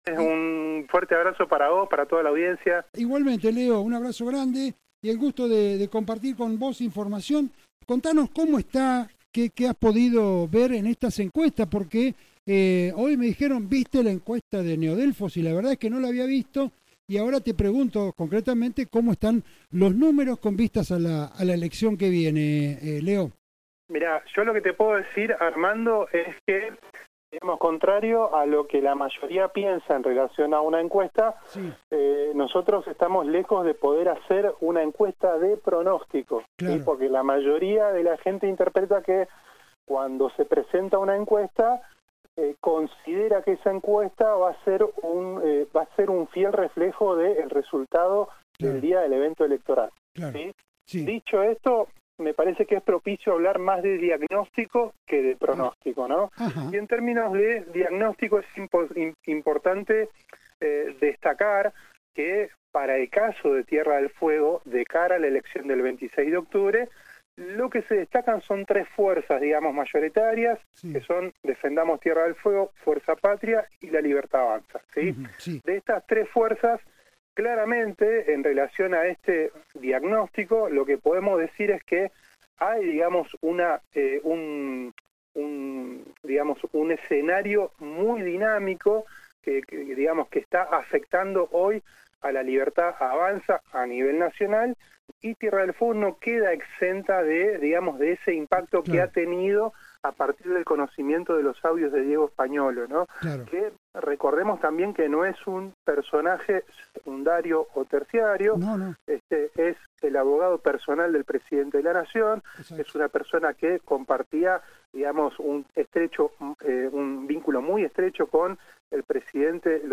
Dialogamos